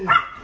dog
bark_27061.wav